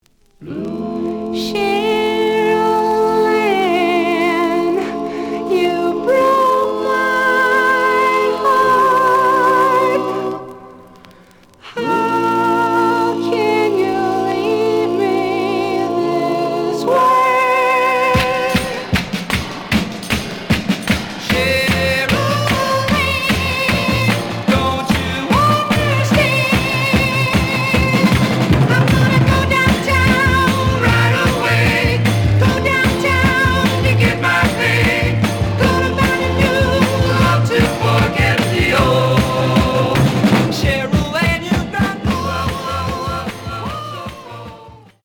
試聴は実際のレコードから録音しています。
●Genre: Rock / Pop
A面の序盤若干ノイジーだが、全体的にプレイはまずまず。)